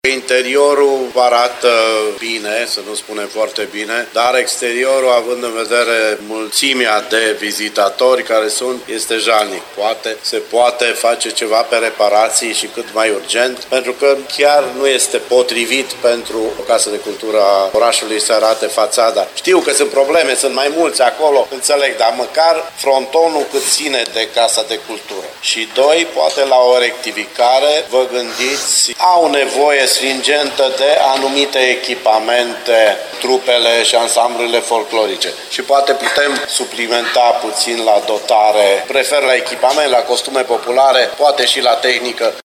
Cu ocazia votării Agenderi Culturale, consilierul local Simion Moșiu a ridicat problema reabilitării fațadei Casei de Cultură și a dotării instituției, în special cu costume.